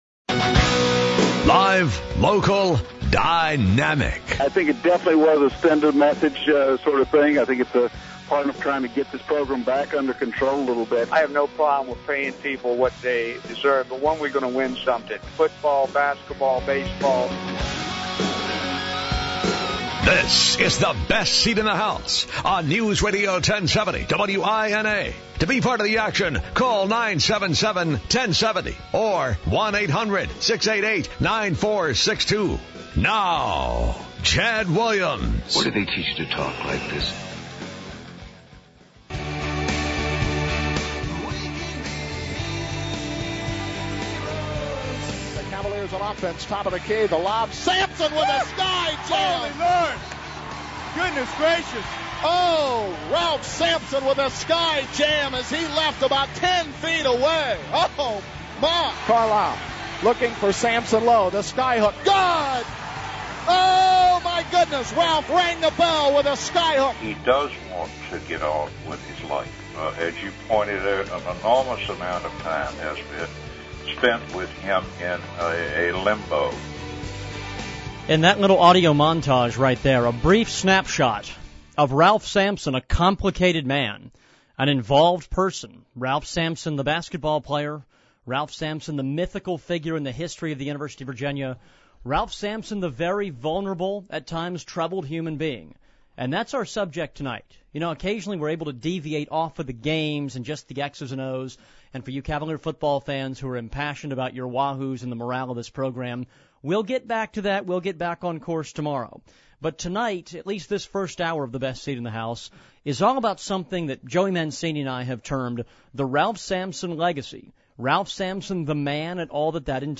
takes listener calls to understand how fans feel.